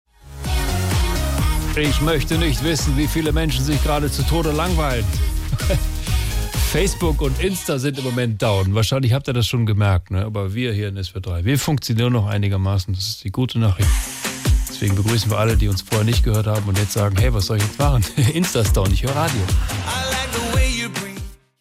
Nachrichten Facebook und Insta down – aber das Radio funktioniert noch